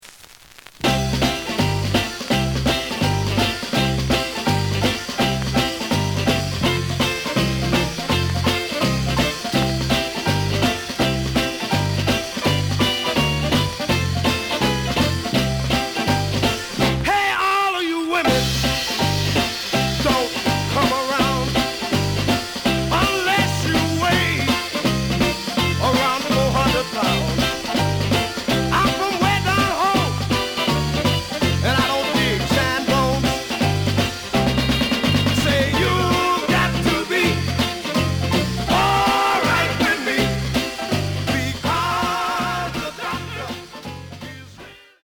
The audio sample is recorded from the actual item.
●Genre: Rhythm And Blues / Rock 'n' Roll
A side plays good.